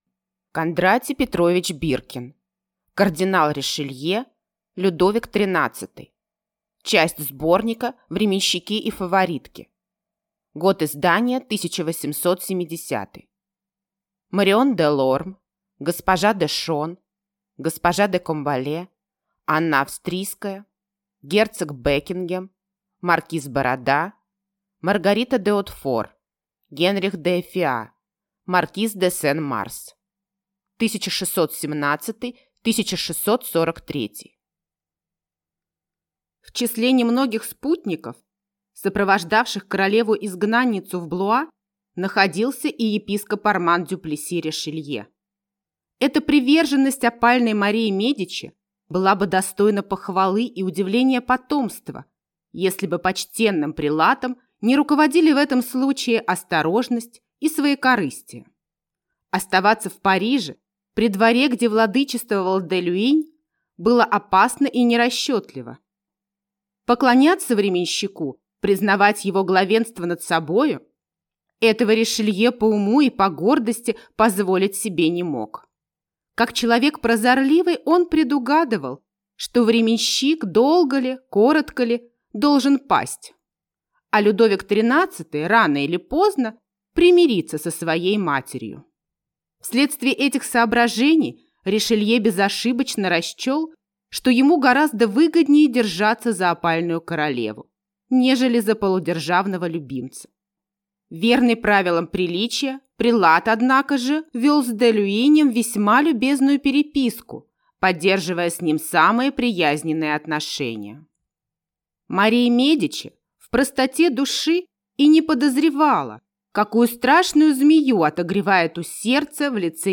Аудиокнига Кардинал Ришелье. Людовик XIII | Библиотека аудиокниг